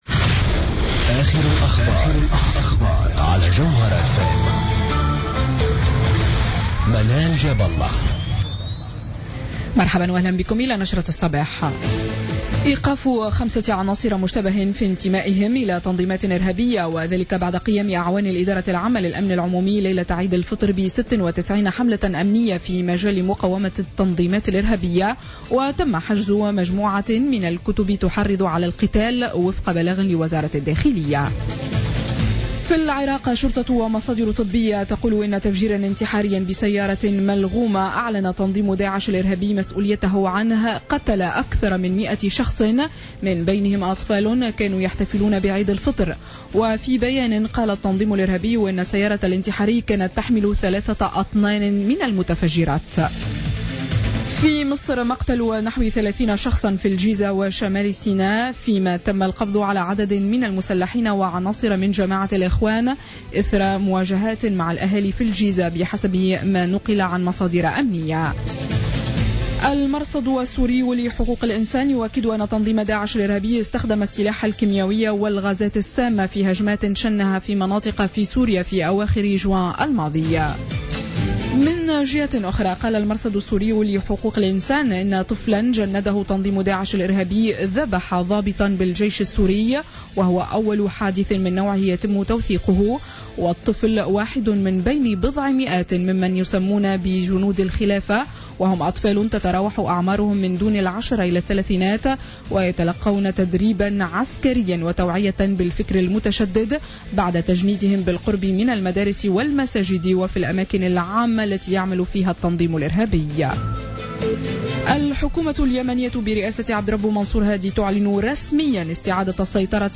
نشرة أخبار السابعة صباحا ليوم السبت 18 جويلية 2015